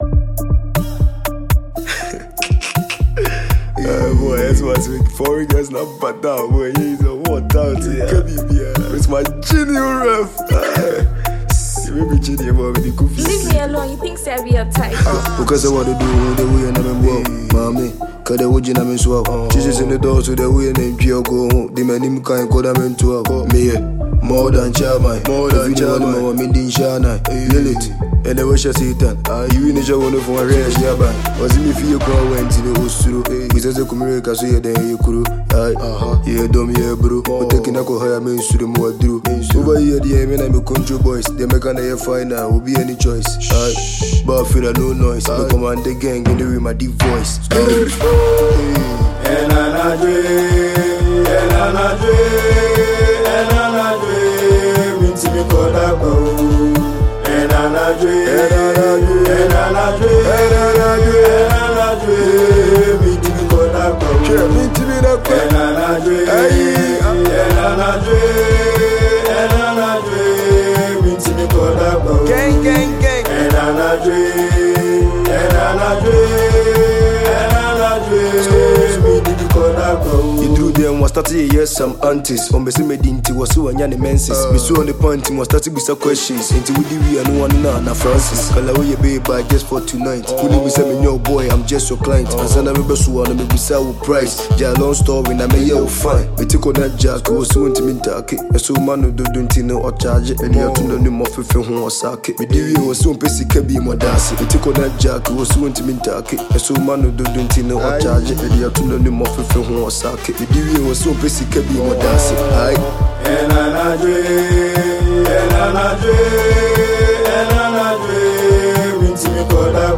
Asakaa rapper